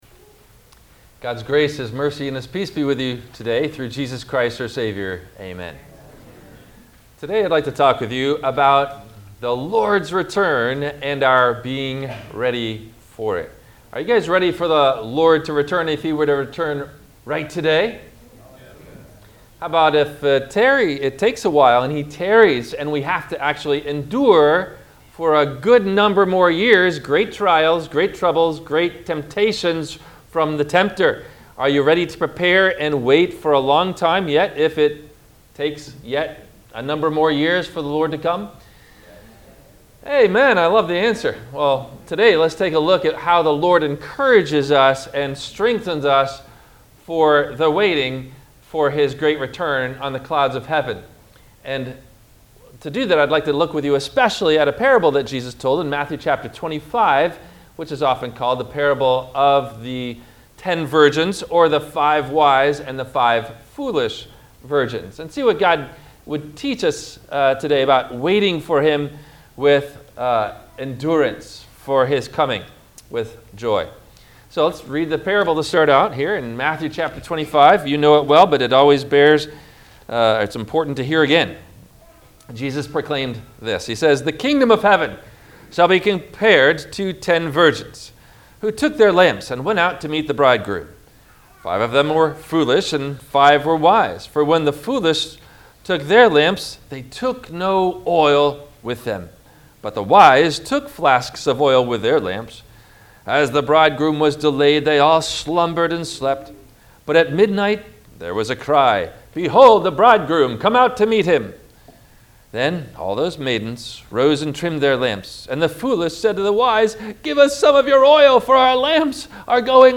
NOTE: THE MP3 PLAYER AUDIO ONLY SERMON BUTTON BELOW THE YOUTUBE VIDEO, IS AVAILABLE FOR WHEN YOU CANNOT WATCH A VIDEO.